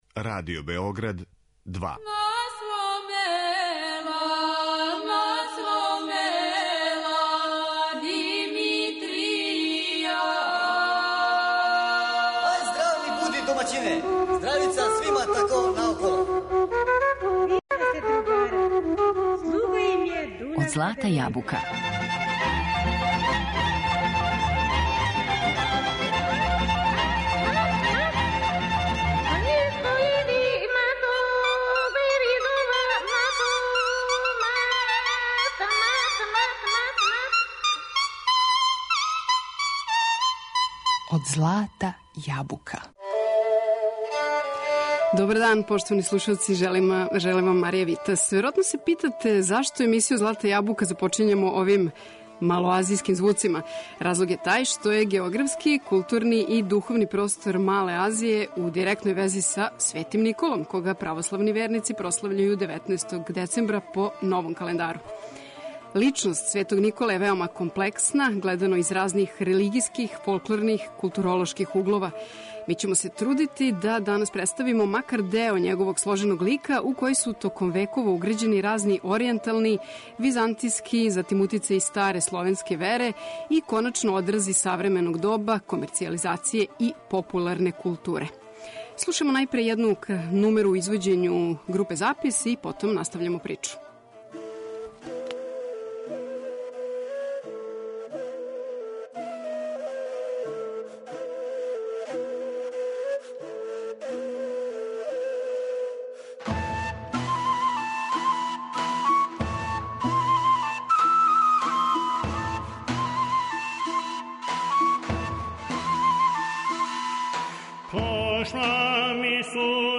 Како је Никола, епископ античког града Мире из 4. века, својим рођењем и деловањем везан за простор Мале Азије, током емисије ћемо, осим српске, слушати и примере из грчког и турског музичког наслеђа, уз нумере из македонског и руског фолклора, јер је и у овим земљама Свети Никола веома уважен.